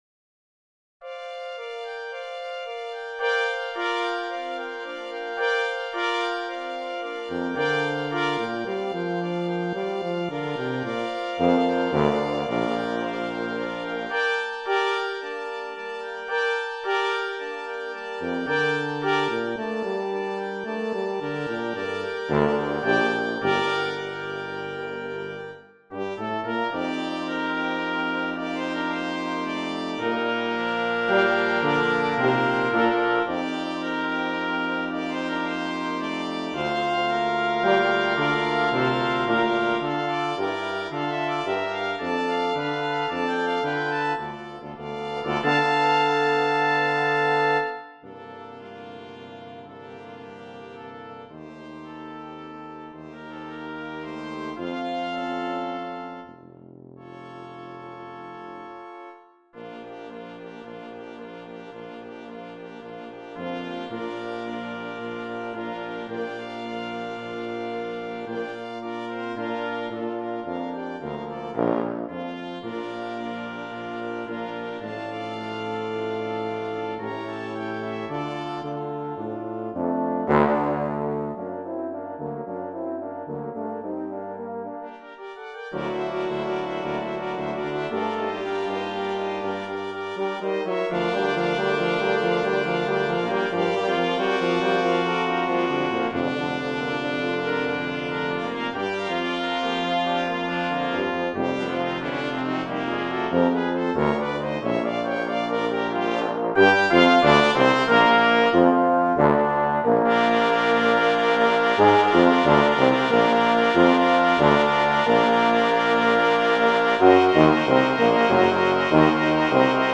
Voicing: Double Brass Quintet